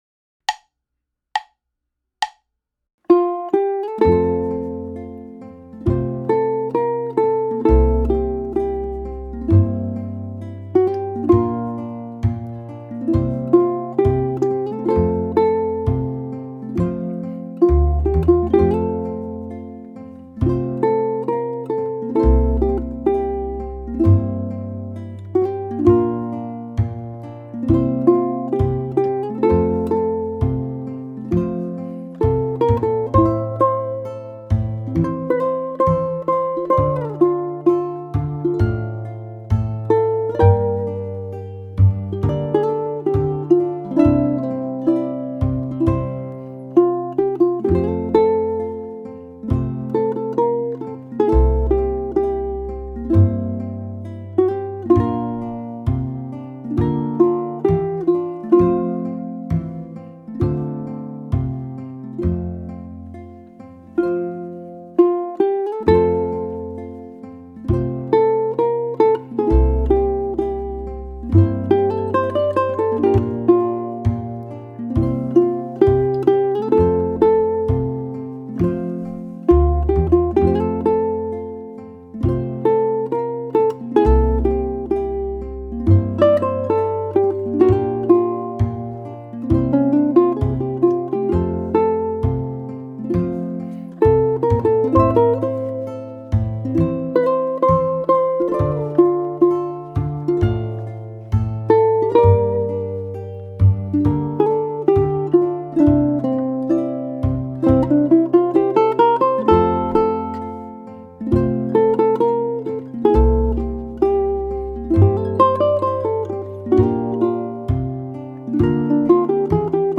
Aim for a mellow andante tempo.
Down 2X Strum | Strum on beats 1 and 3.
ʻukulele